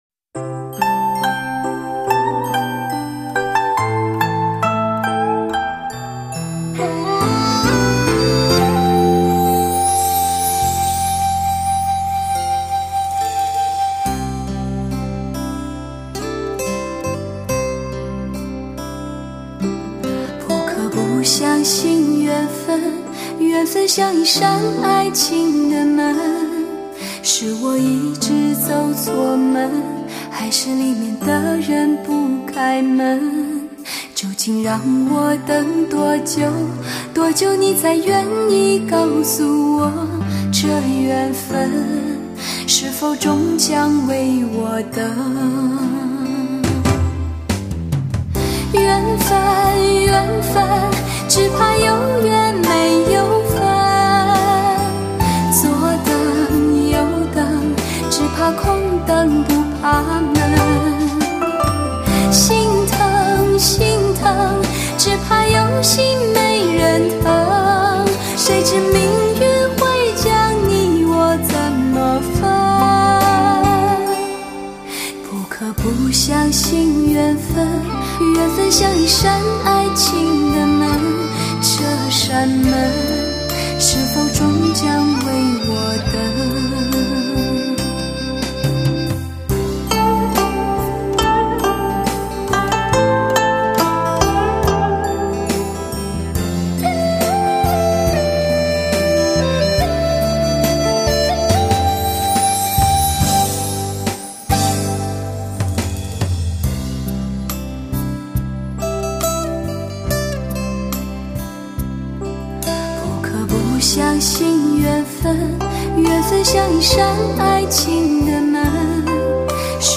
2010)音色似山泉，气质若明月
类型: HIFI试音